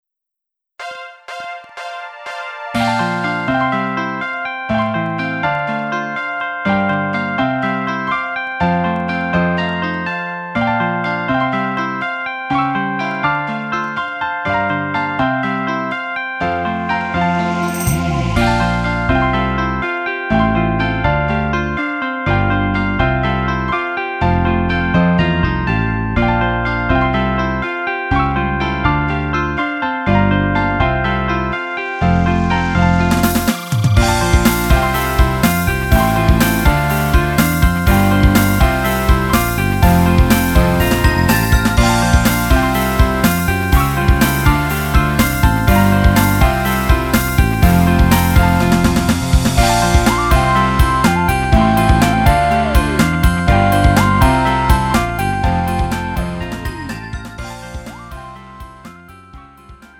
음정 원키 3:43
장르 가요 구분